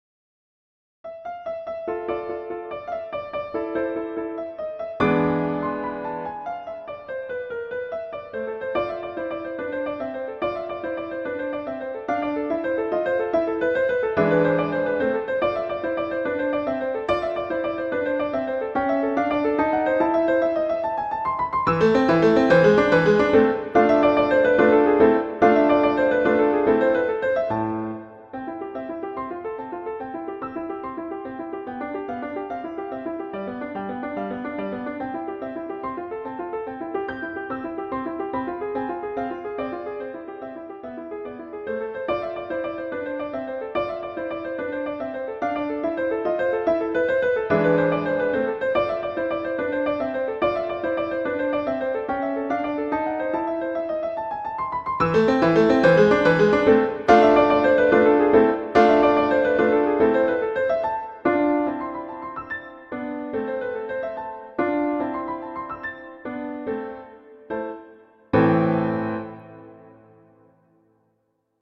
classical, instructional